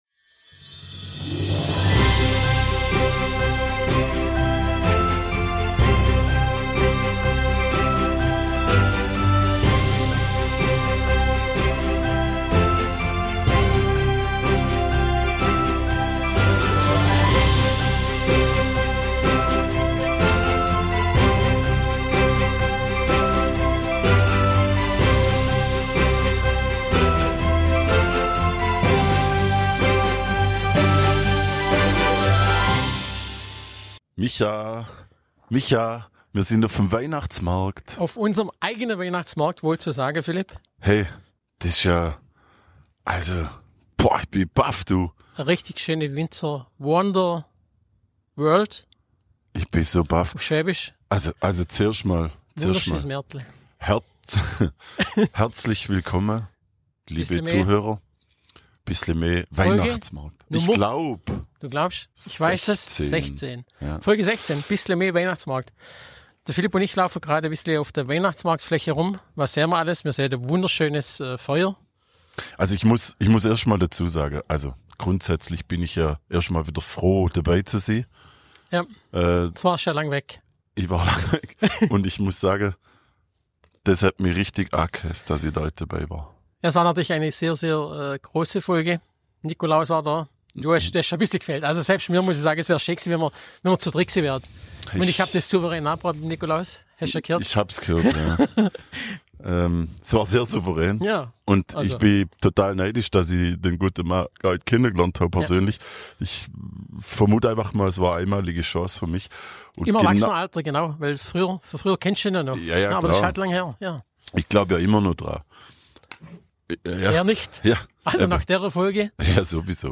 #16 bissle me goes Weihnachtsmarkt ~ Bissle me – Schwoba-Podcast aus´m Schlofsack Podcast